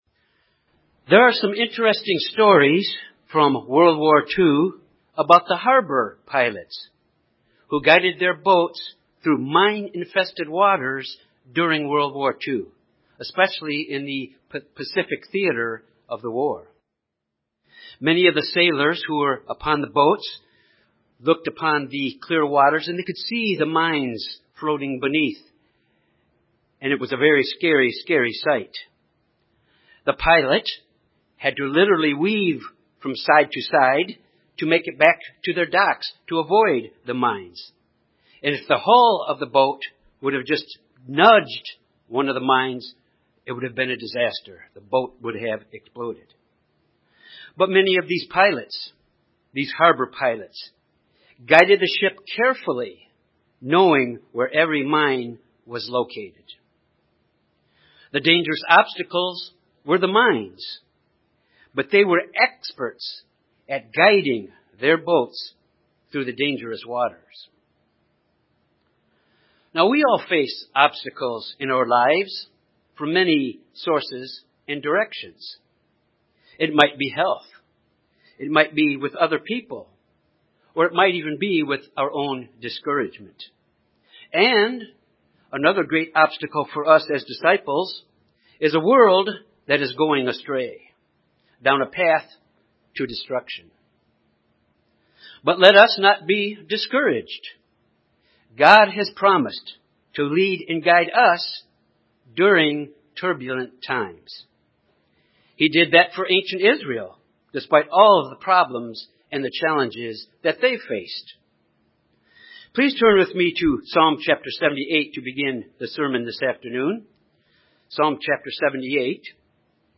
As disciples of Jesus Christ, we will face obstacles in life. This sermon focuses on the guidance that God gives His disciples to be able to overcome these obstacles.